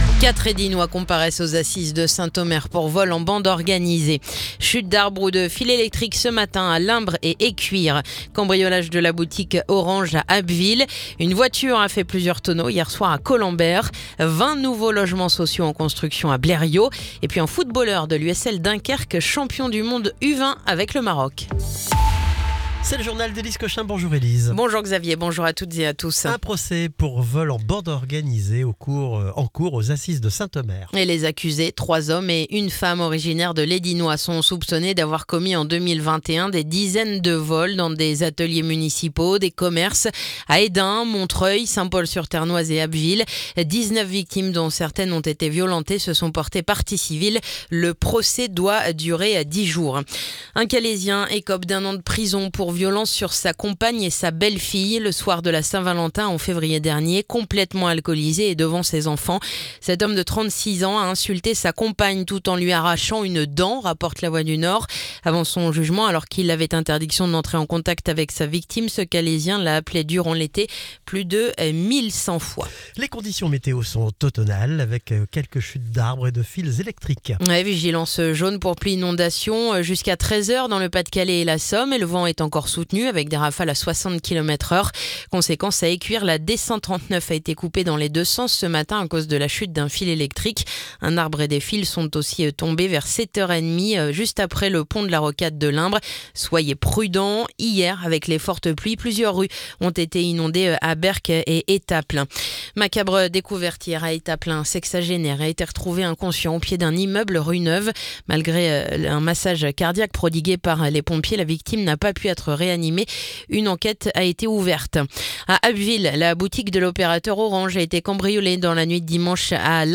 Le journal du mardi 21 octobre